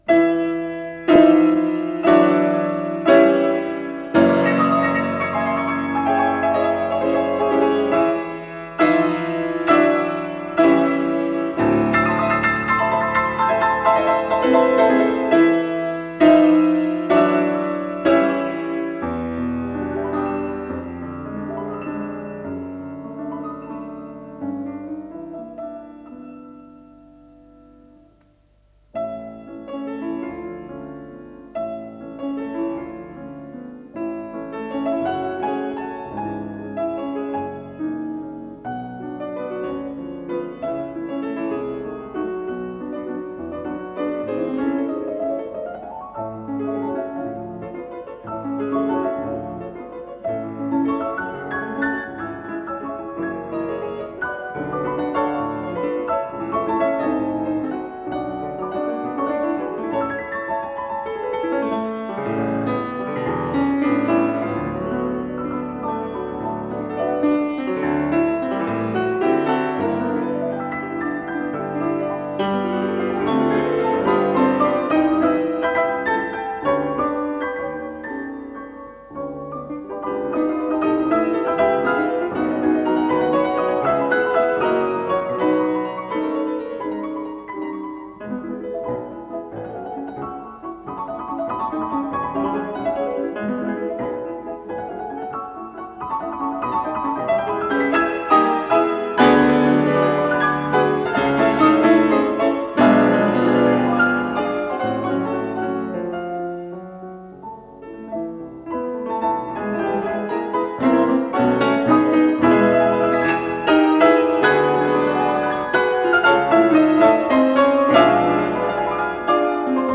с профессиональной магнитной ленты
Ля мажор.
фортепиано